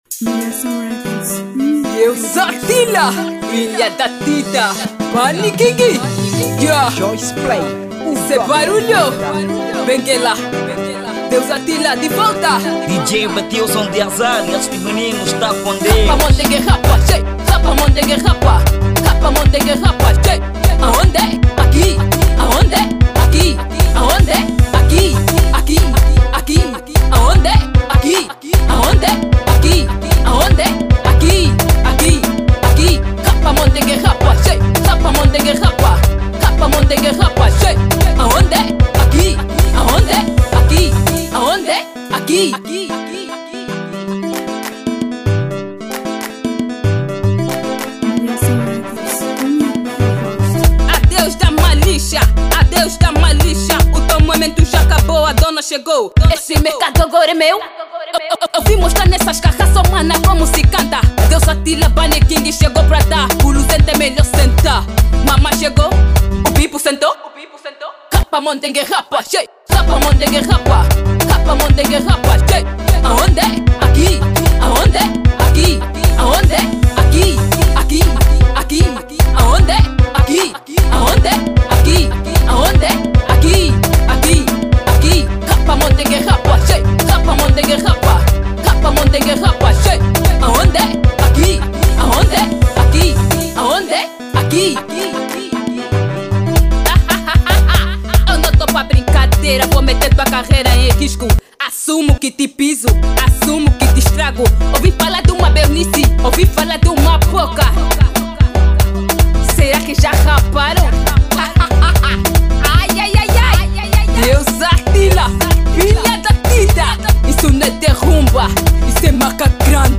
Categoria: Kuduro